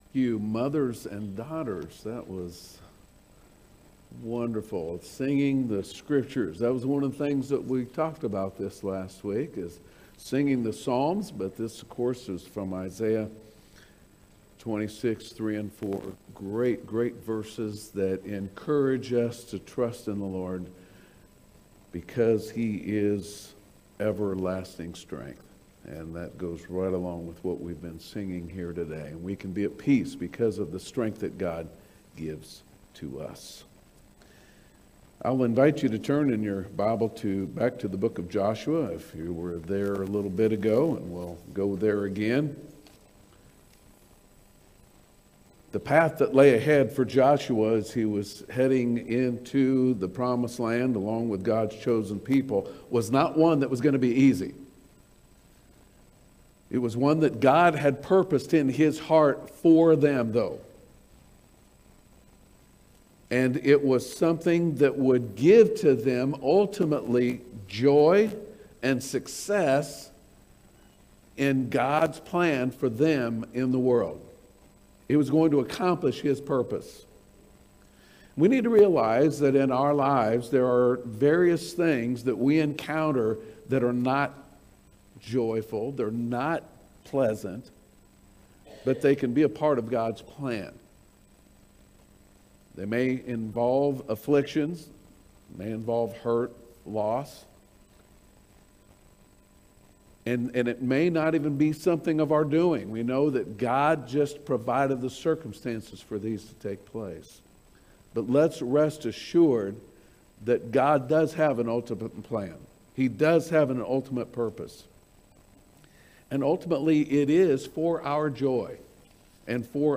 Sermons - Meridian Baptist Church